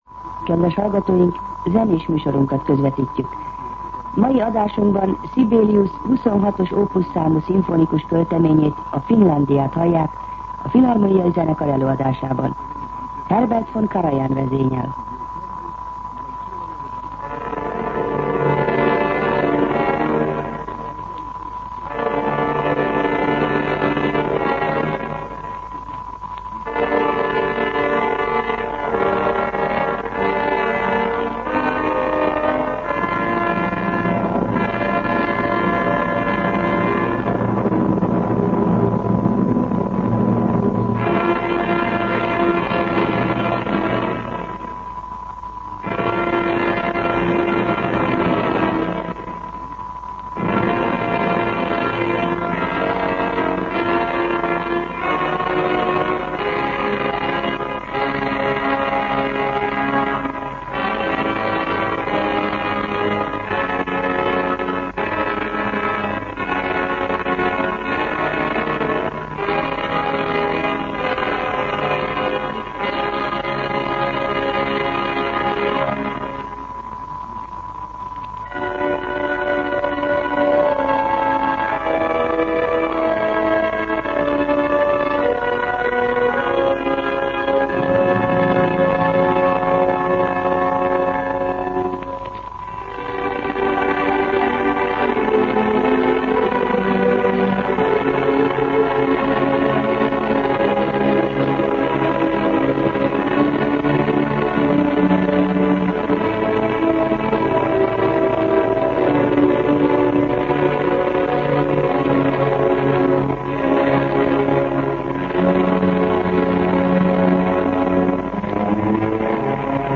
Mai adásunkban Sibelius 26-os Opus számú szimfonikus költeményét, a Finlandiát hallják a Filharmónia Zenekar előadásában. Herbert von Karajan vezényel. zene Információk Adásba került 1956-10-30 7:35 Hossz 0:04:41 Cím Zene Műsor letöltése MP3